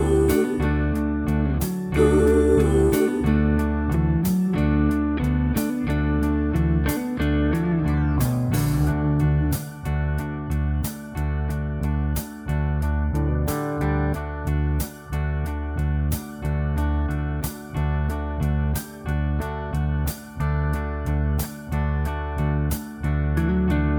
Duet Version Pop (1980s) 5:28 Buy £1.50